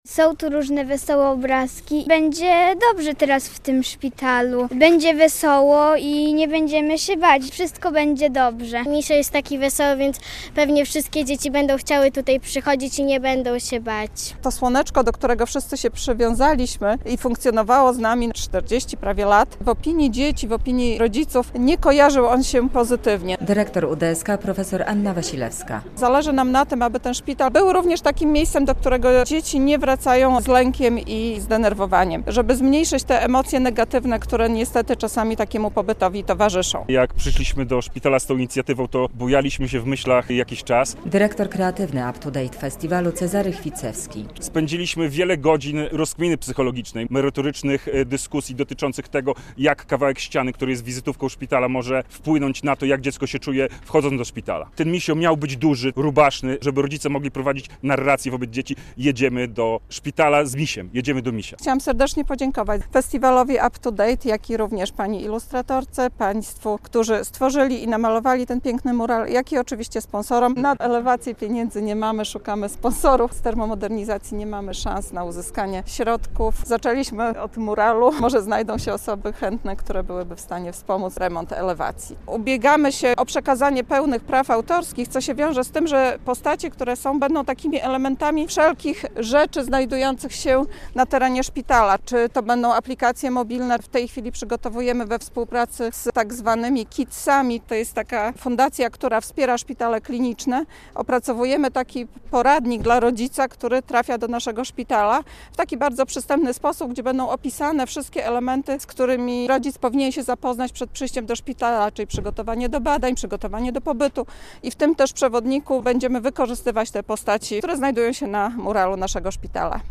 Nowy mural wita małych pacjentów UDSK - relacja